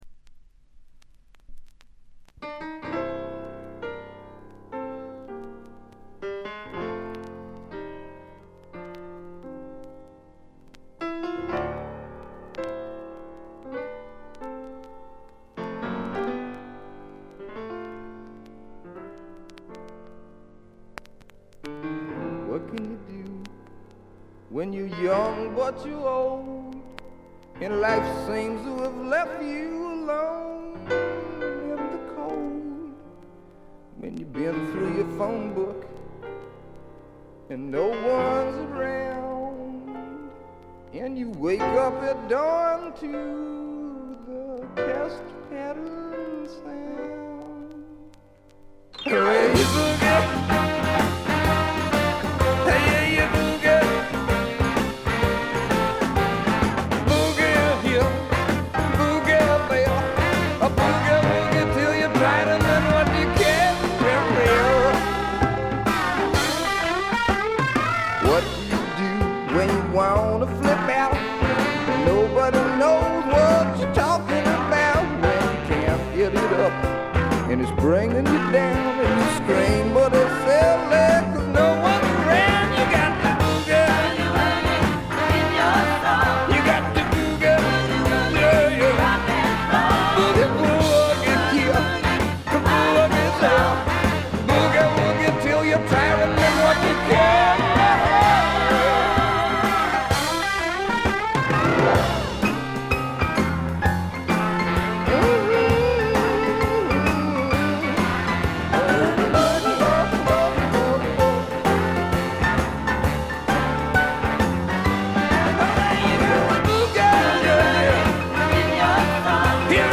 特別に目立つノイズはありません。
西海岸製スワンプ系シンガーソングライターの裏名盤です。
試聴曲は現品からの取り込み音源です。